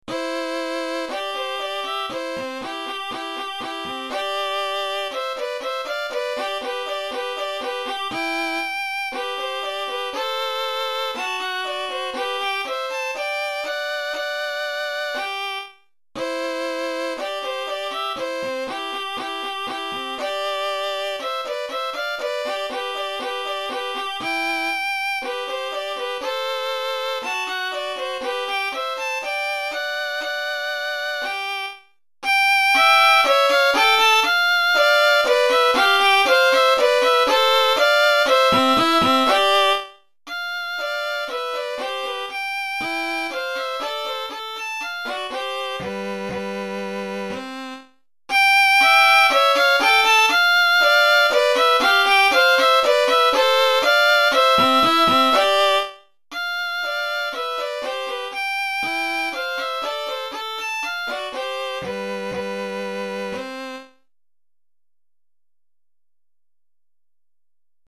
Répertoire pour Violon - 2 Violons